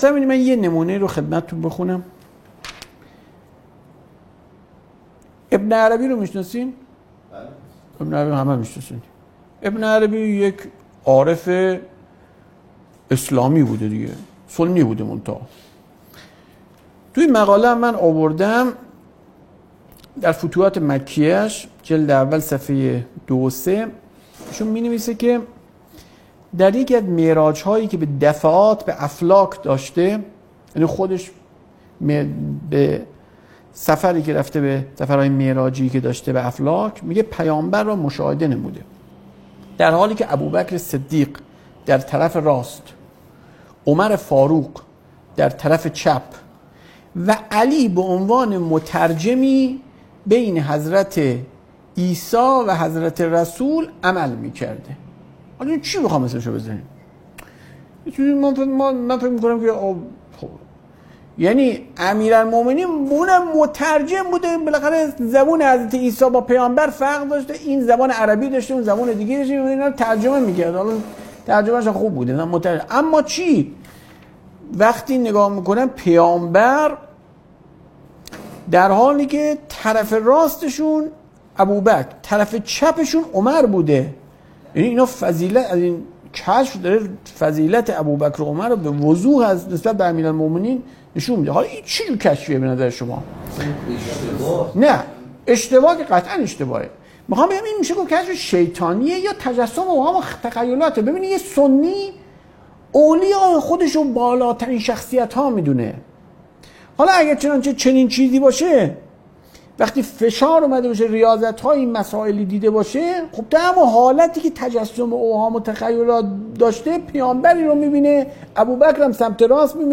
صوت ســـخنرانی: